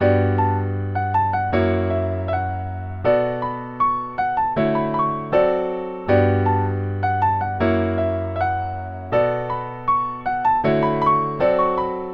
Tag: 158 bpm Hip Hop Loops Piano Loops 2.04 MB wav Key : Unknown FL Studio